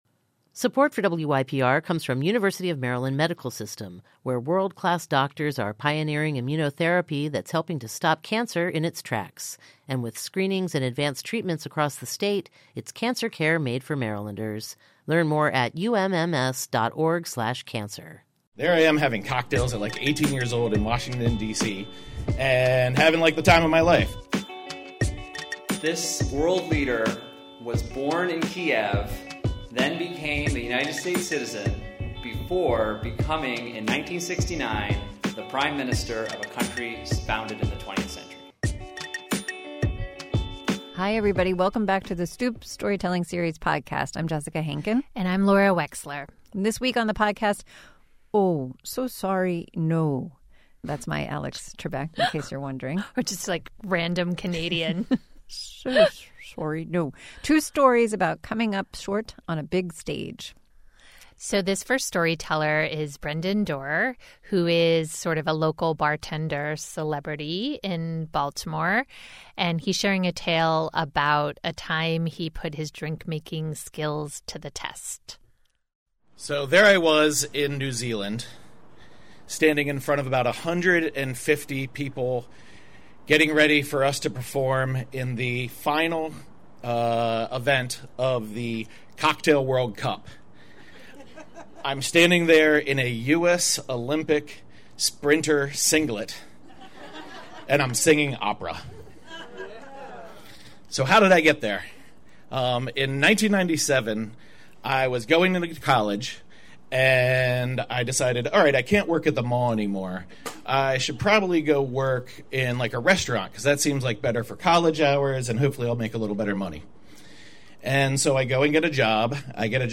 The Stoop Storytelling Series “Oh, So Sorry, No!”